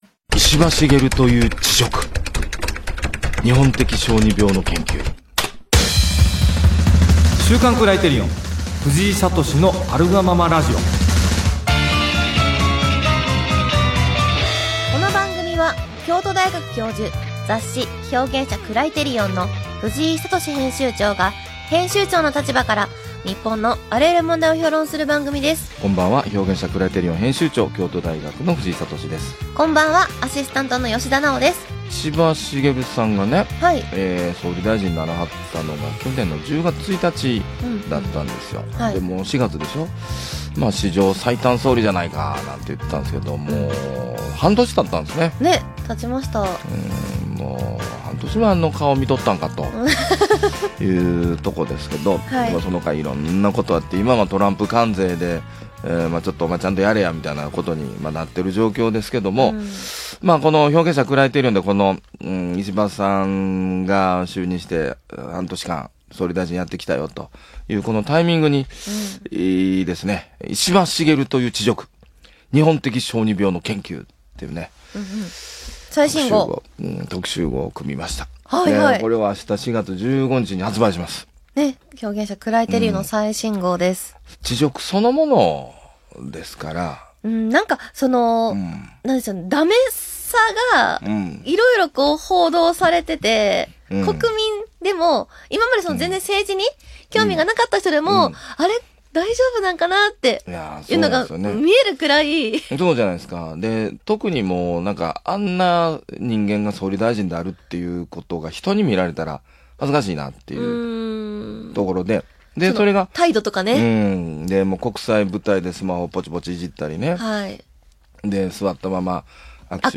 【ラジオ】「石破茂」という恥辱 ～『日本的小児病』の研究～